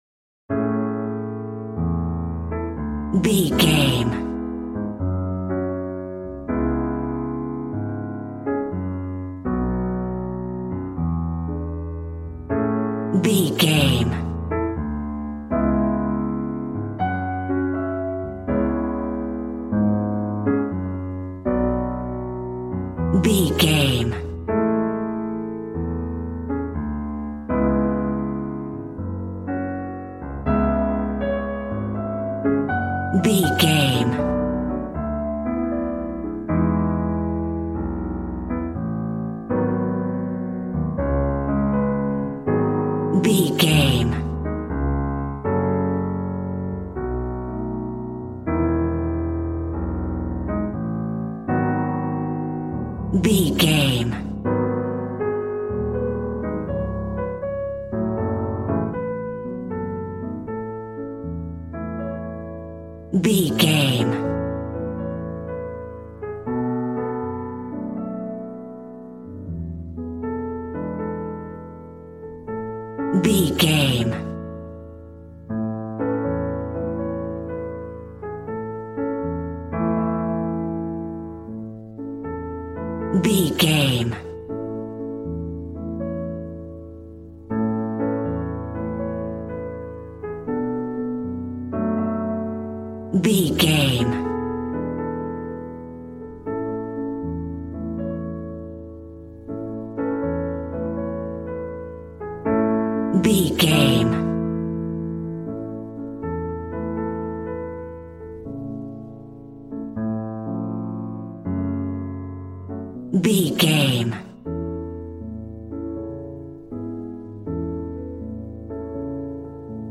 Aeolian/Minor
smooth
drums